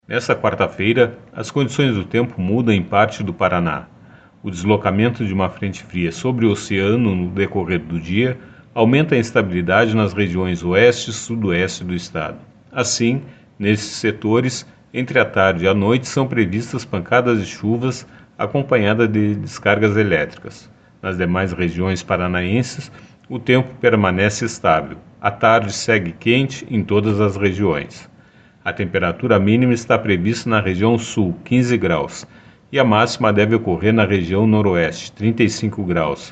No Paraná, o avanço de uma frente fria aumenta a instabilidade nas regiões oeste e sudoeste do estado. Ouça os detalhes na previsão do Simepar com o meteorologista